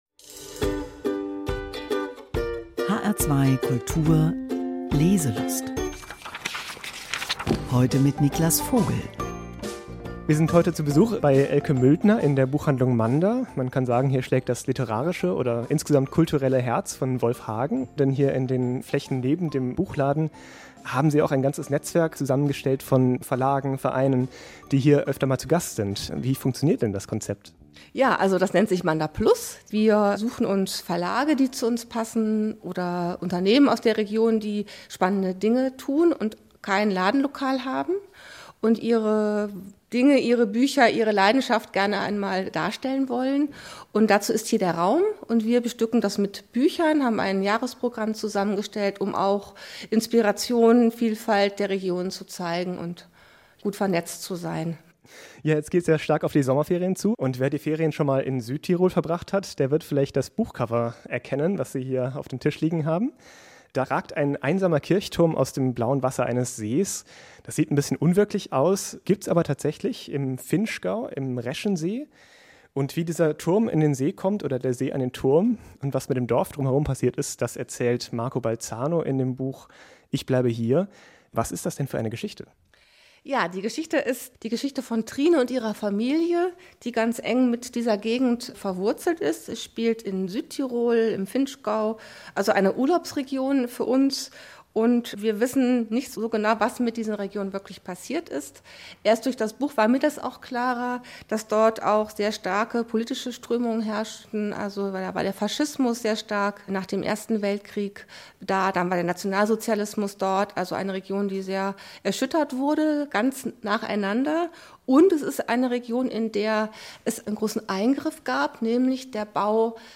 Buchvorstellung auf HR2